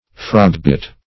frogbit \frog"bit`\, frog's-bit \frog's-bit\, n. (Bot.)